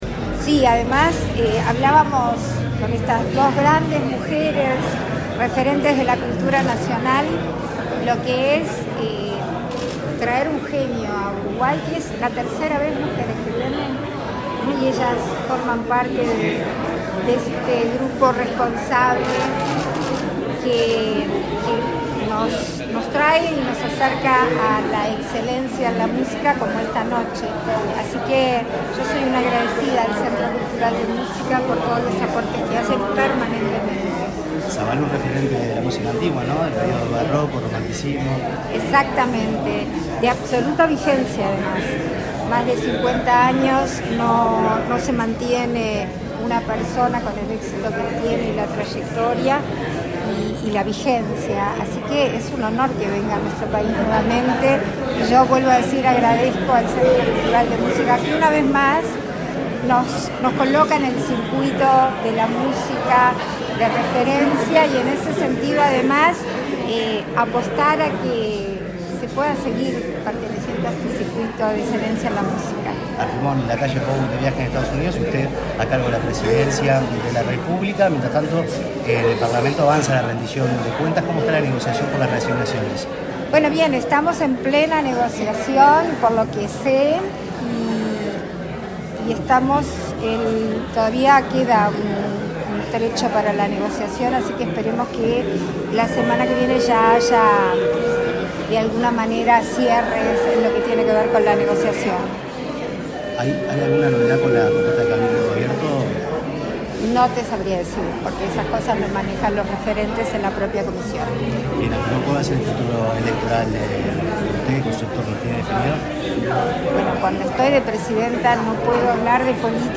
Declaraciones de la presidenta en ejercicio, Beatriz Argimón, a la prensa
Este domingo 17, la presidenta de la República en ejercicio, Beatriz Argimón, dialogó con la prensa en el teatro Solís, al asistir al concierto de